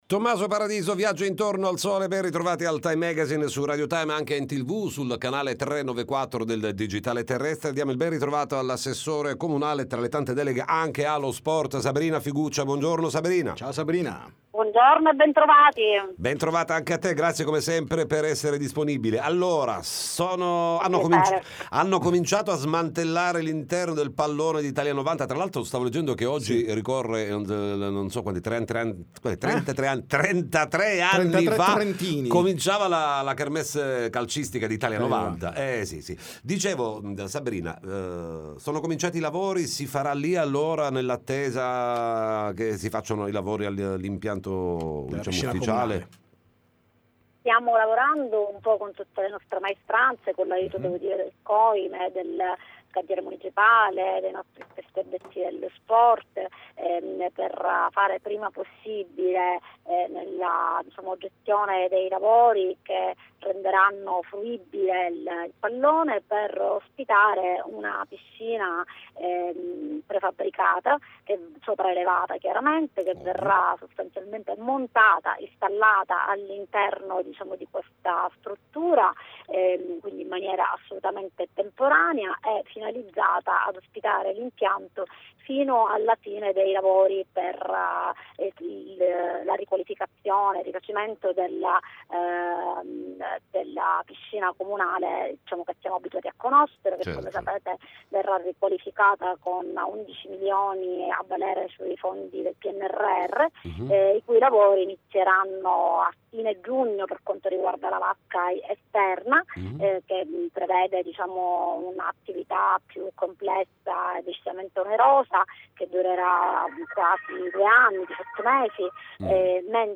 Time Magazine Intervista l’ass. com. Sabrina Figuccia